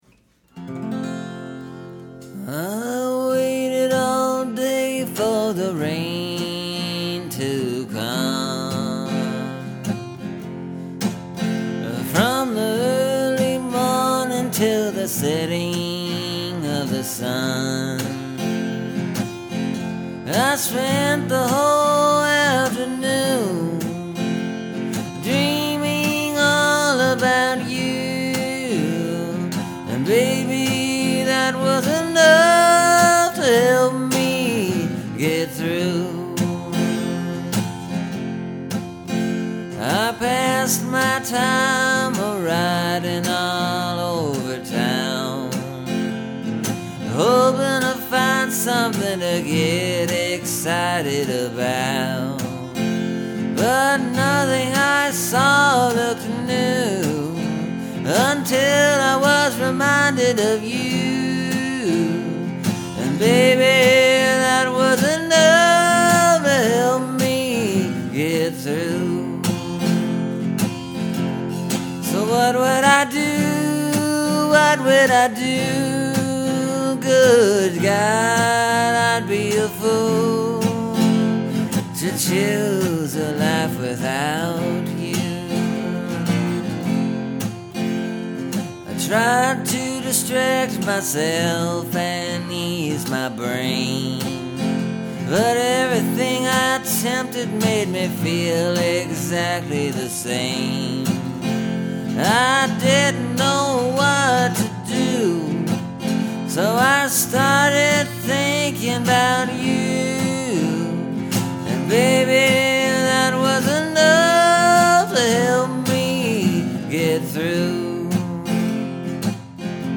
This one’s a pretty straight up blues progression. Nothing too fancy, although the singing is pretty intricate.